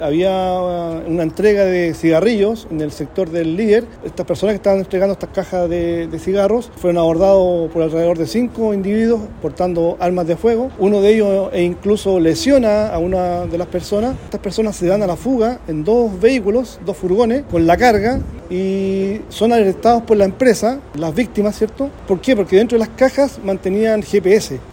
Pero lo que no sabían los antisociales era que las cajetillas de cigarros tenían GPS. Así lo confirmó el General de la Octava Zona de Carabineros, Renzo Miccono.